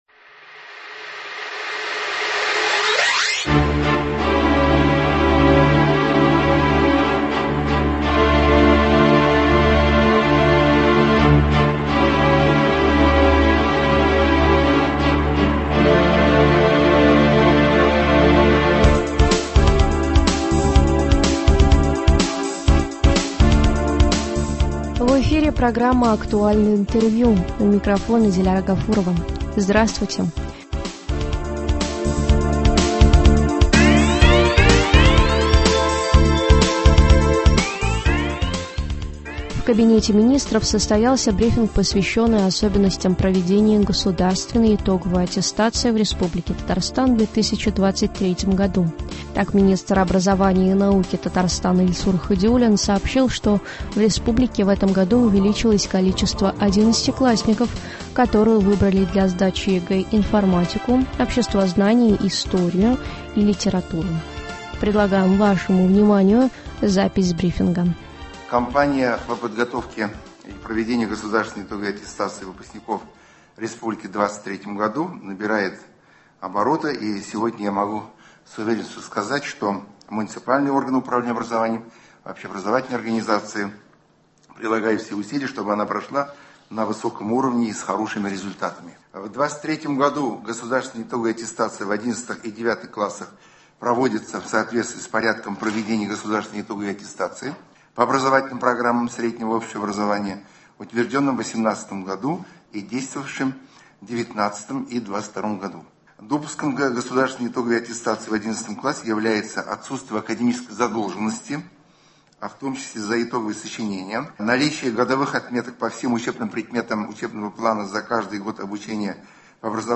Актуальное интервью (22.03.23)